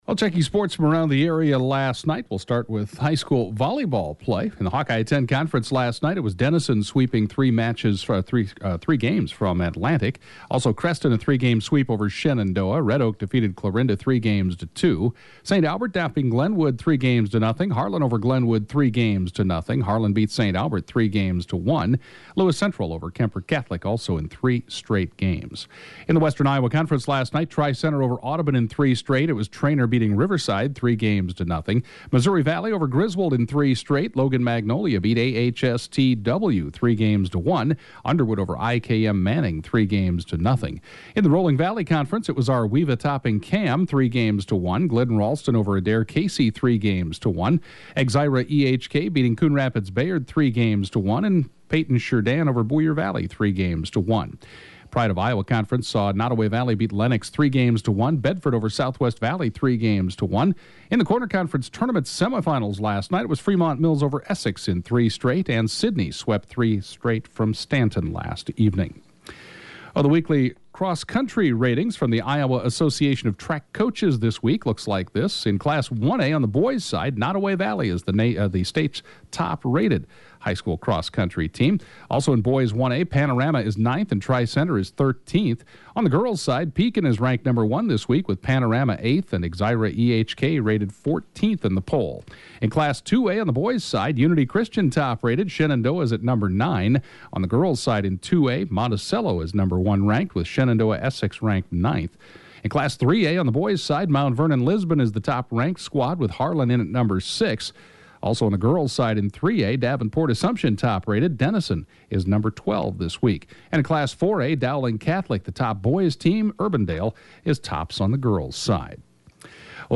(Podcast) KJAN Morning Sports report, 11/06/2015